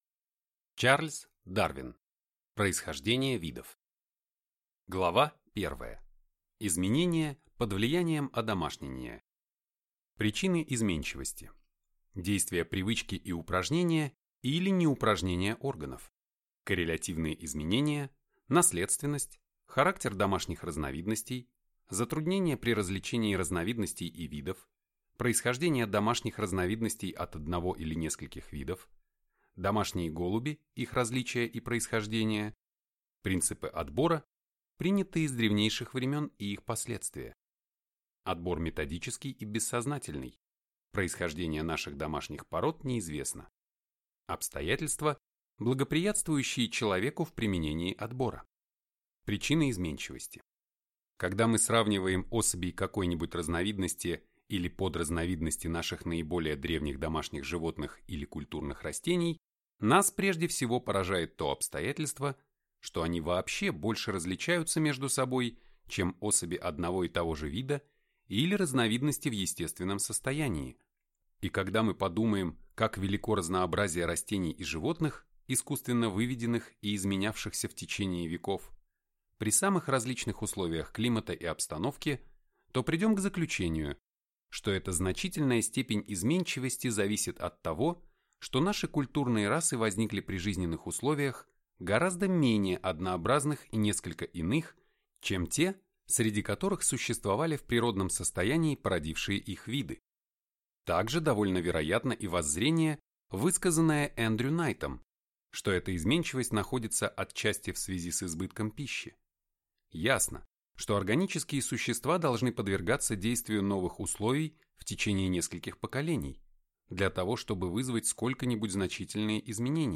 Аудиокнига Происхождение видов путем естественного отбора | Библиотека аудиокниг